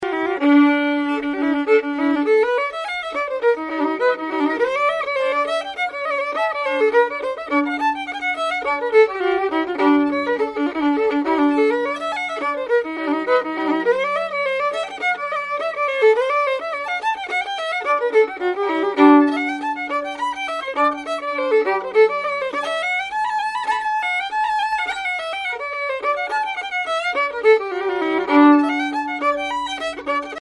Fiddle
REELS
Recorded in Camden Town, London,